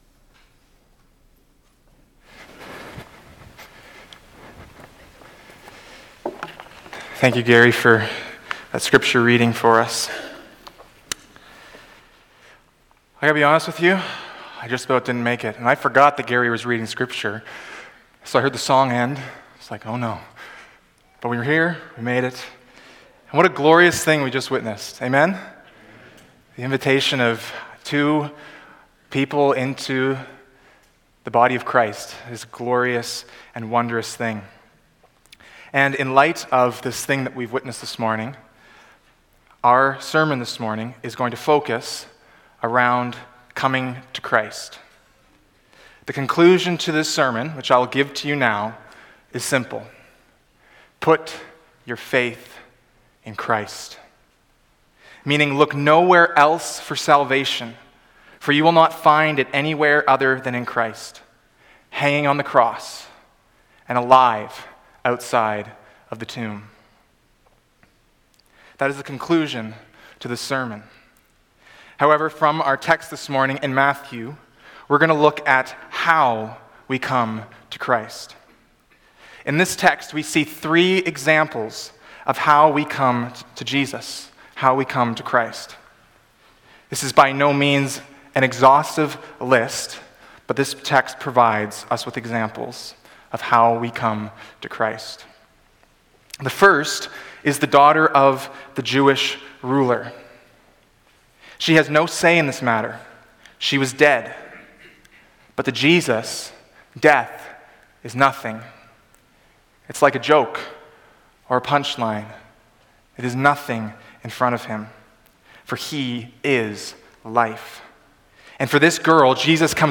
Sermons | Bridgeway Community Church